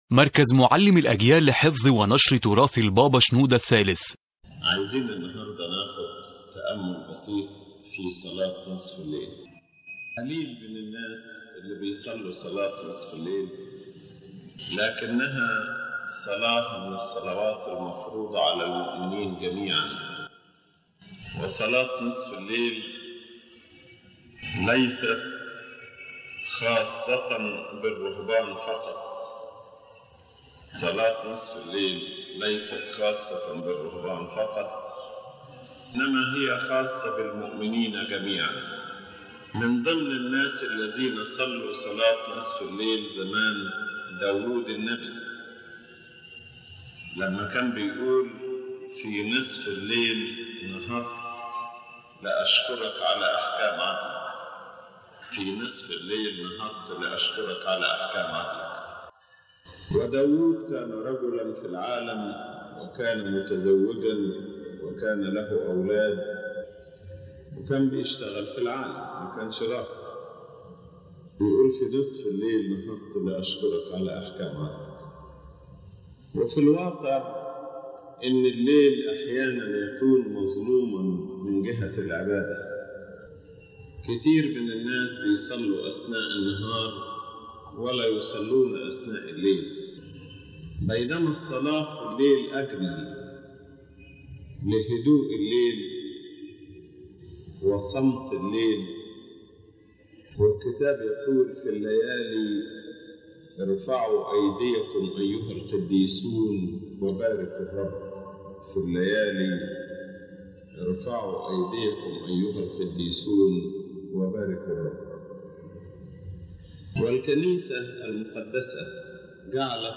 In this lecture His Holiness Pope Shenouda III speaks about the importance of the Midnight Prayer and calls the believers to it, clarifying that it is not exclusively for monks but for all believers.